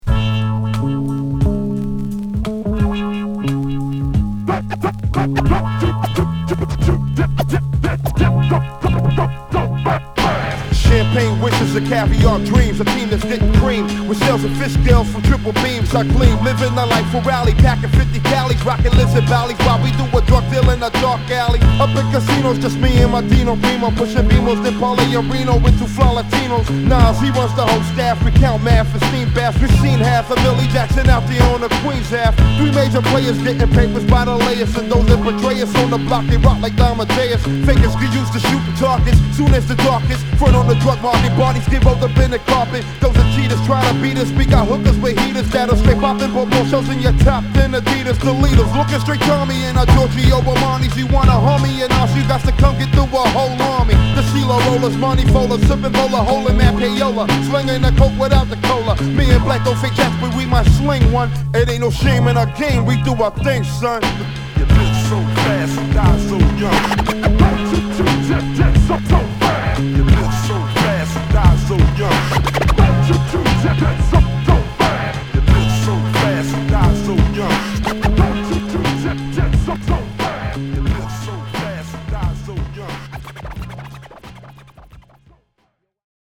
・ HIP HOP 90's 12' & LP